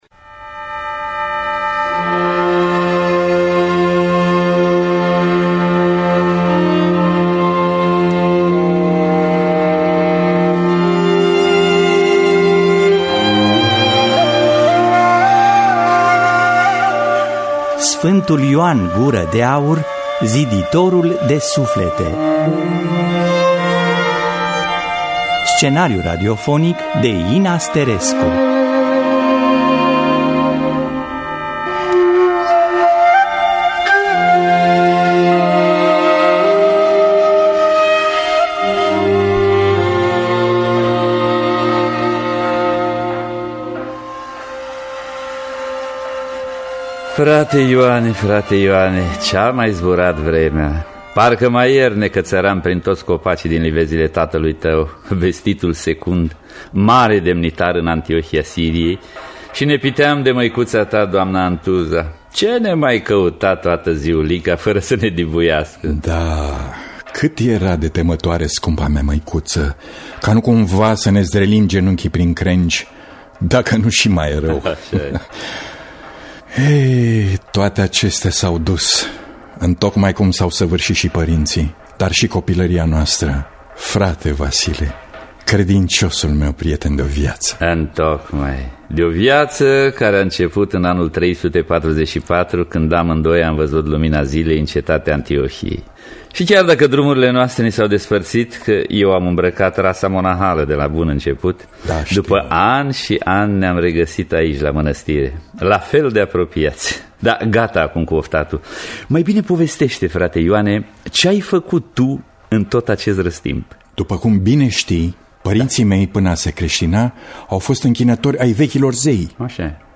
Ioan Gură de Aur. Scenariu radiofonic